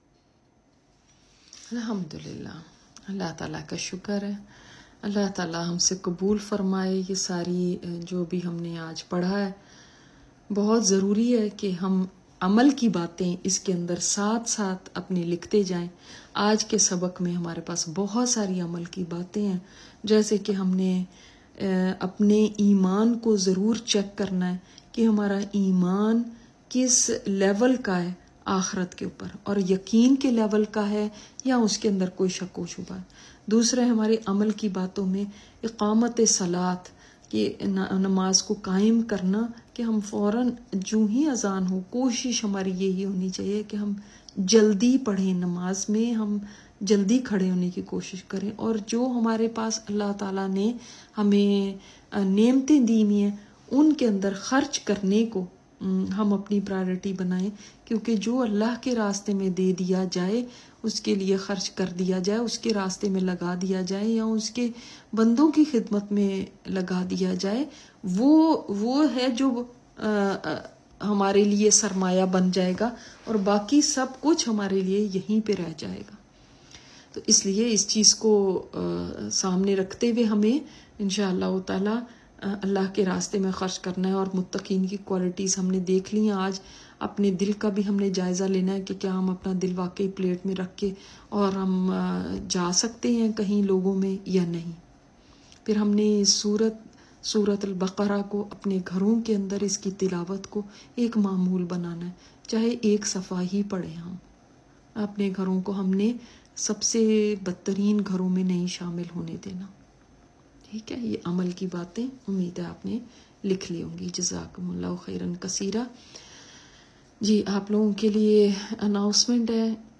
Latest Lecture